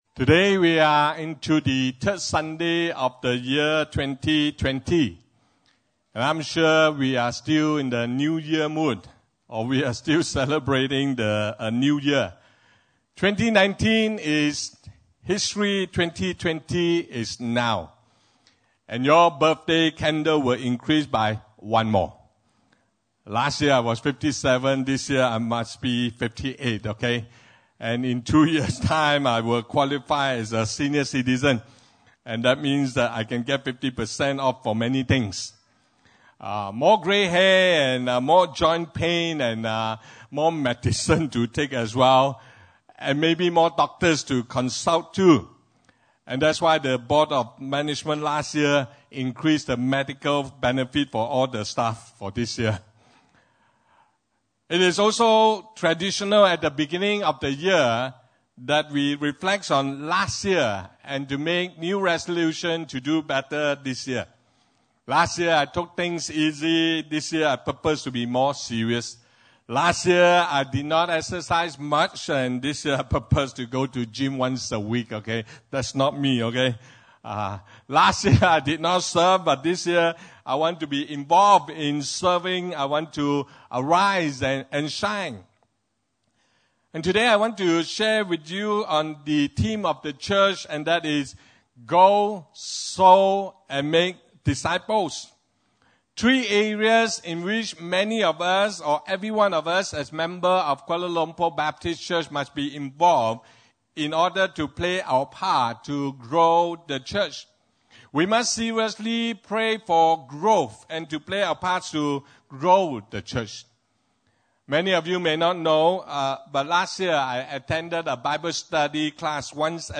Service Type: Sunday Service (English)